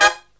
HIGH HIT12-R.wav